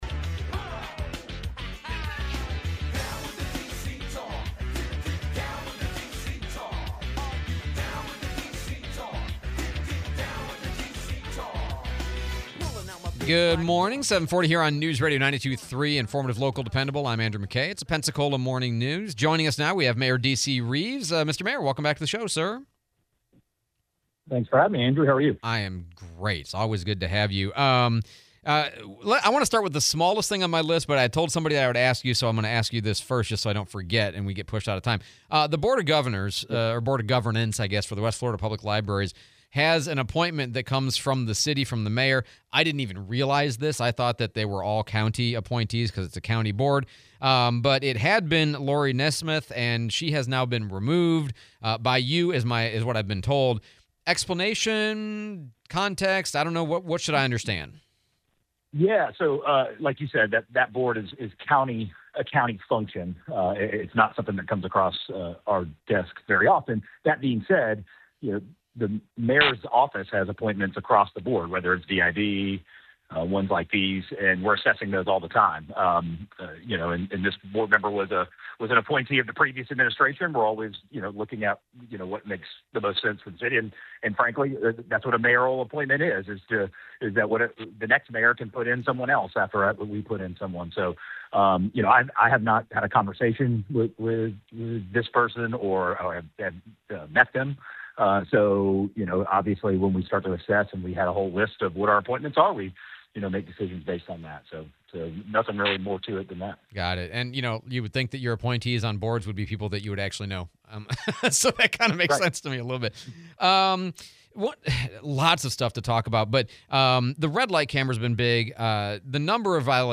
11/19/25 Mayor Reeves interview